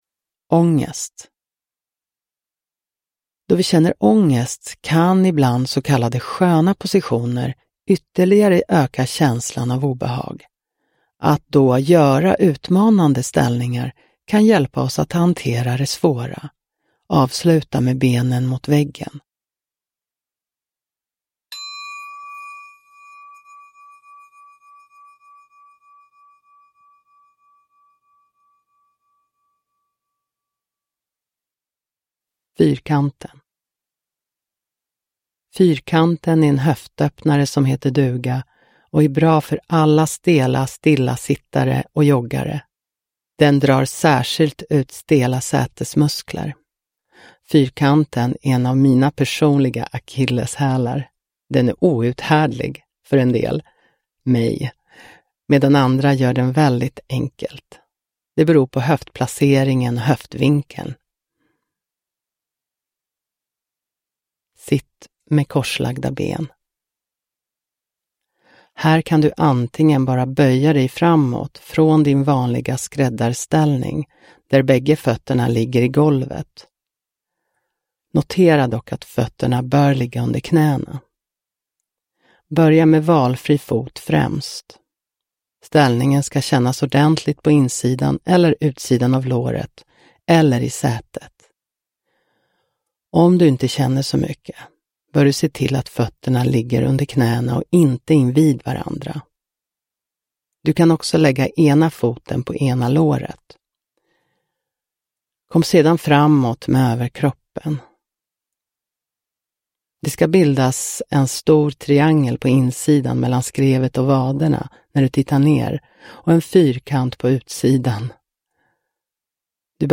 Ångest – Ljudbok – Laddas ner